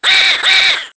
One of King Boo's voice clips in Mario Kart Wii